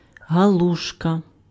^ IPA: [ɦaluʃkɪ], plural in Czech and Slovak; Hungarian: galuska [ˈɡɒluʃkɒ]; Ukrainian: галушка, romanizedhalushka [ɦɐˈɫuʃkɐ]